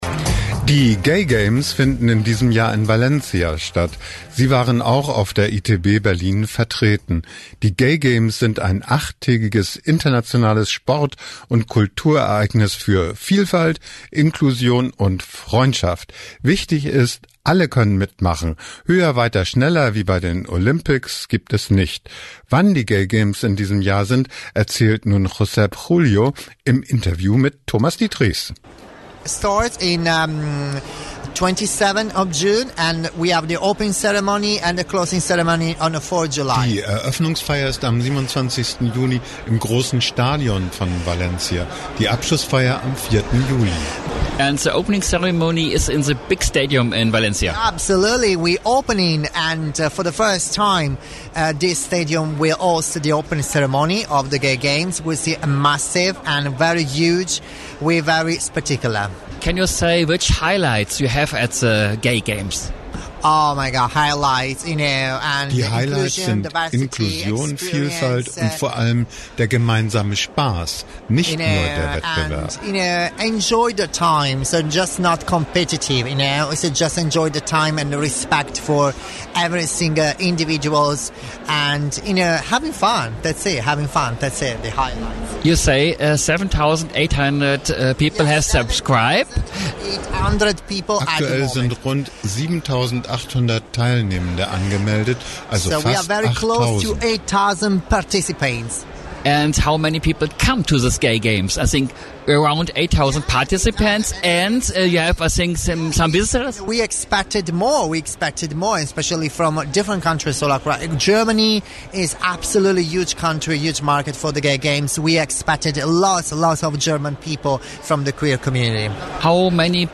Die Gay Games sind in diesem Jahr in Valencia. Interview